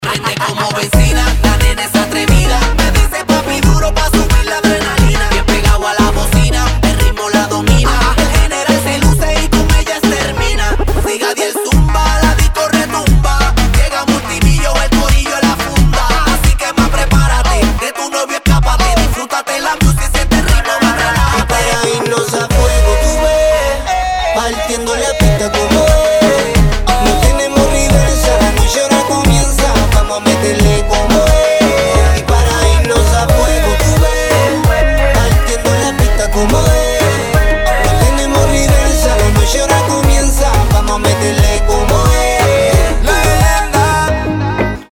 • Качество: 192, Stereo
Латинский реп - он же Reggaeton!